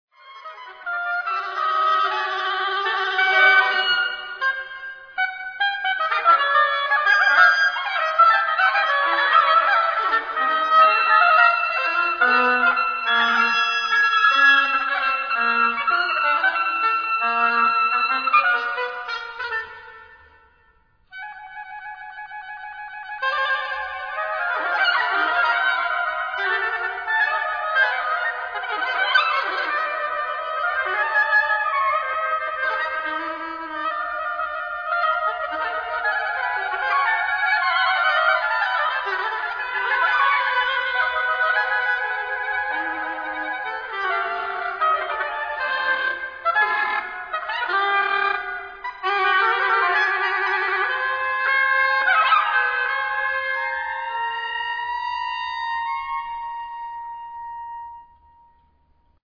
Beautifully matched in sound, intonation, and technique